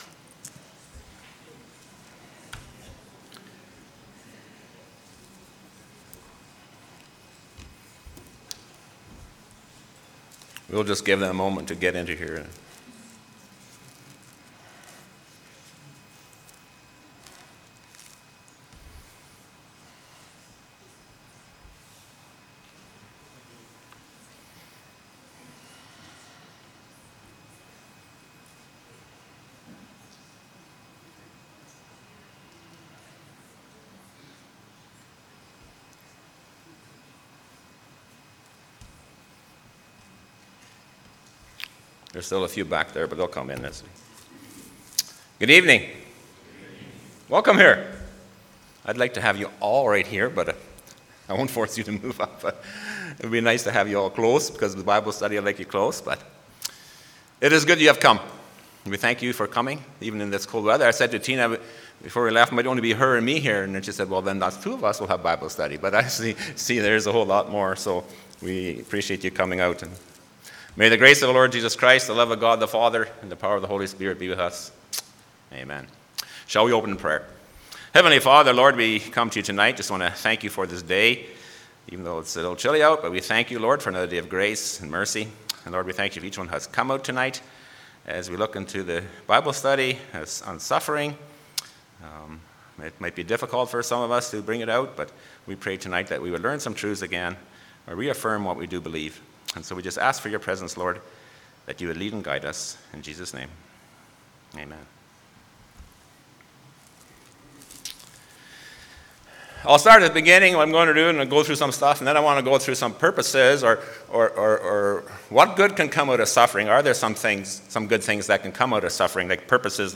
Church Bible Study – Apologetics